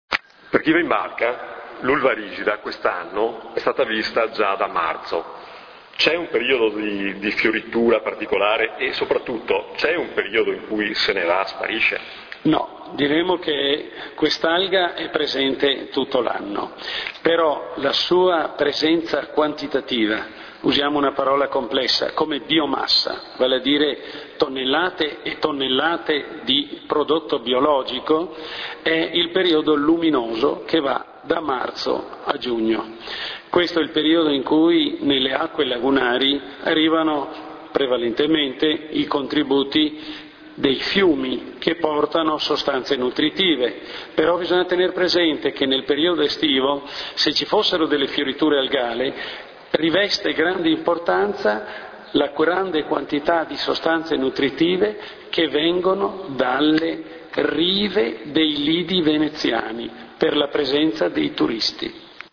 L'intervista           [... attiva l'audio, file .mp3]